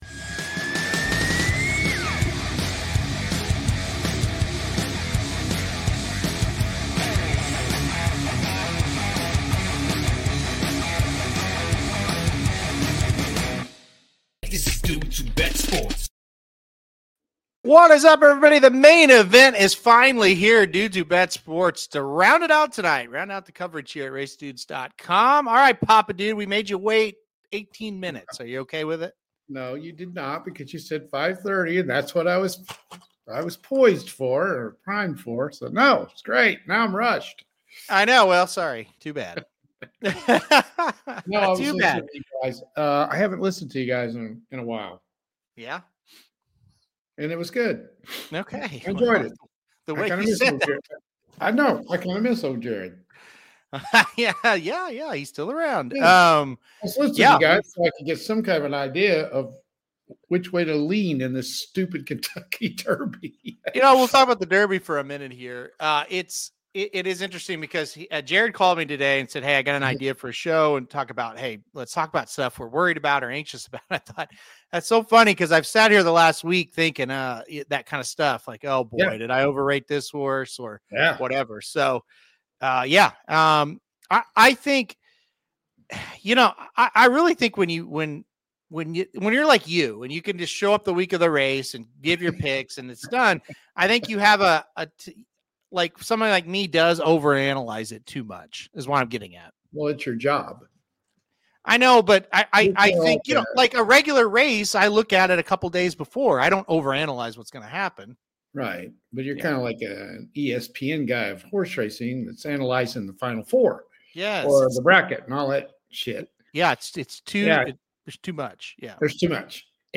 The duo will also answer questions sent in from the listeners.